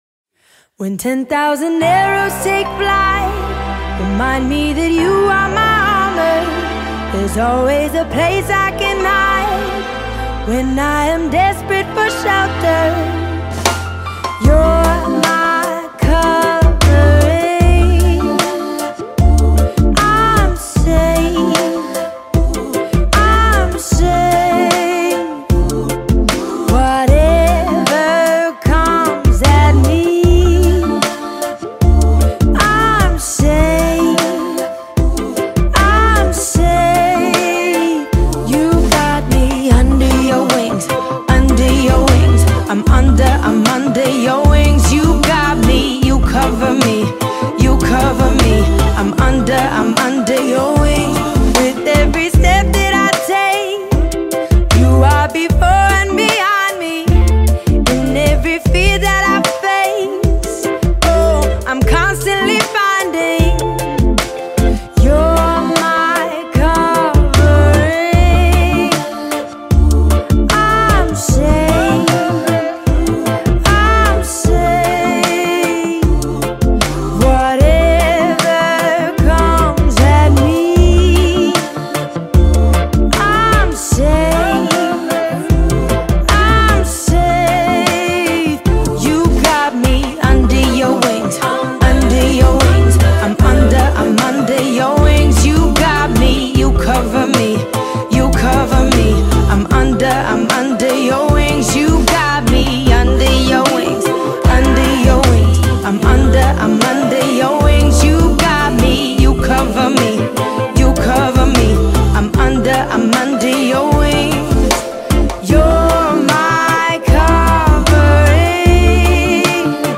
песня
1448 просмотров 1657 прослушиваний 220 скачиваний BPM: 152